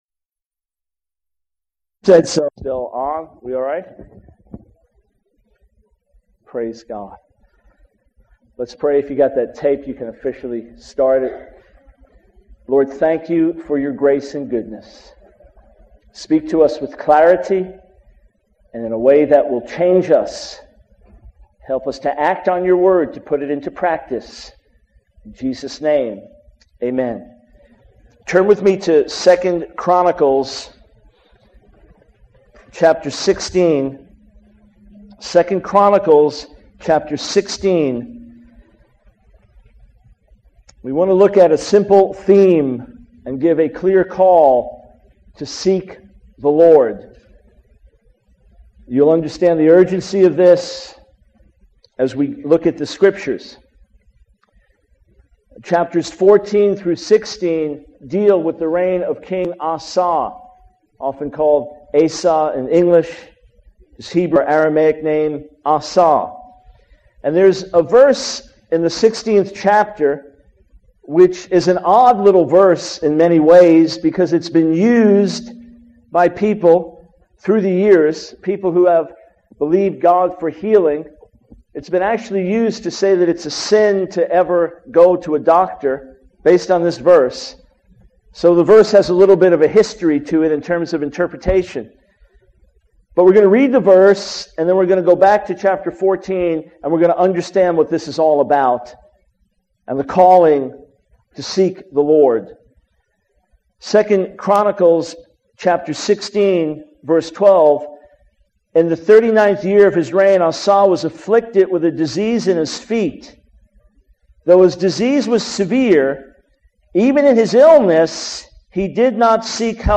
In this sermon, the speaker discusses the challenges and difficulties they are facing as a ministry. They talk about the lack of financial support and the feeling of being abandoned by those who used to contribute.